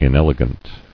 [in·el·e·gant]